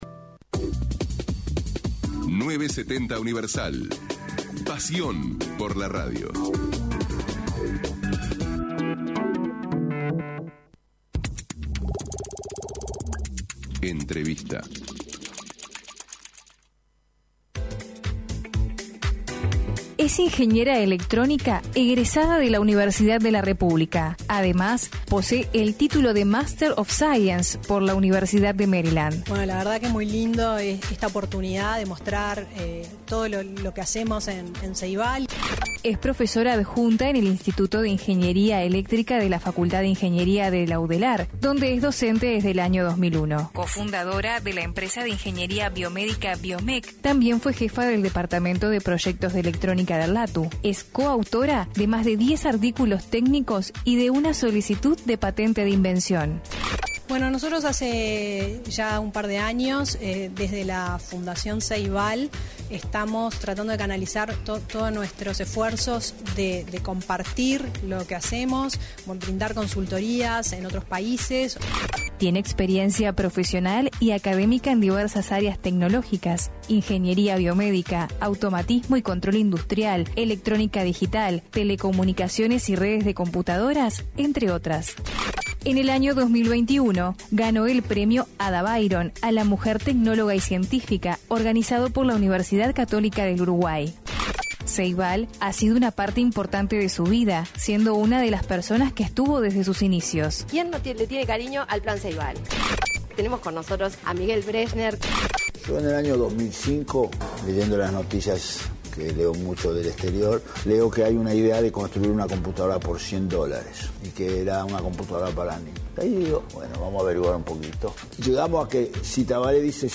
Entrevista a Fiorella Haim (presidenta del Plan Ceibal)